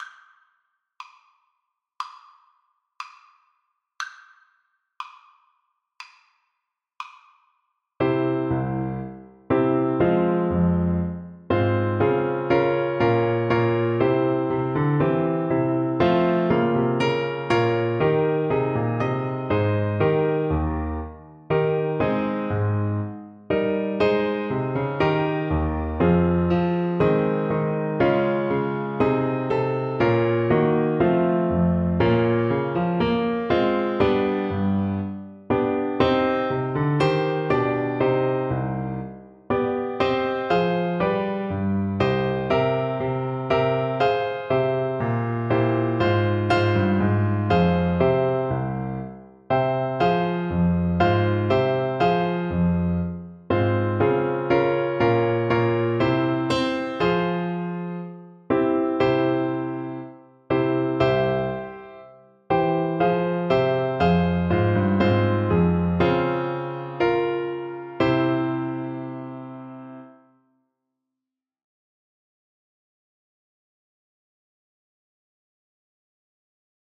Bassoon version
Andante = c.60
4/4 (View more 4/4 Music)
Classical (View more Classical Bassoon Music)